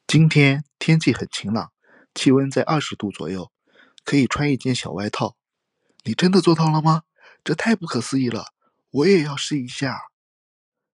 深刻的鼓舞人心的旁白声音
用专为励志故事讲述、哲学思考和电影旁白设计的深沉、共鸣的AI声音吸引您的观众。
文本转语音
电影旁白